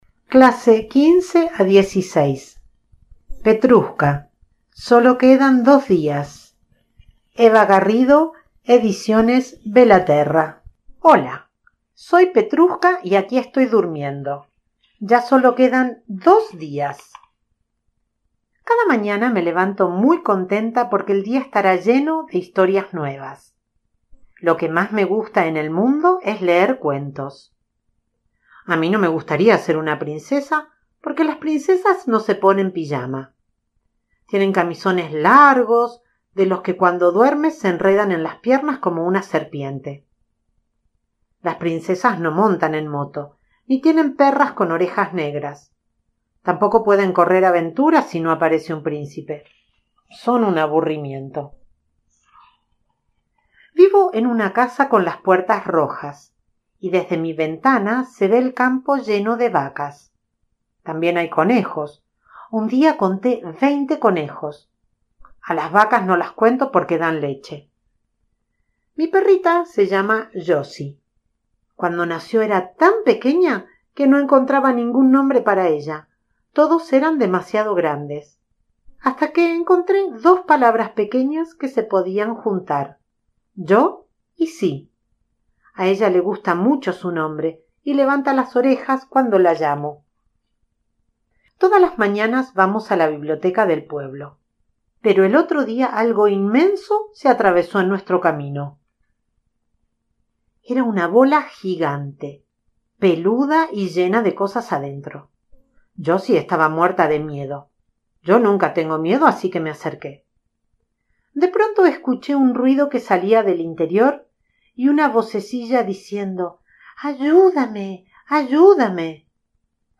Audiolibro: Petruska sólo quedan dos días
Tipo: Audiolibros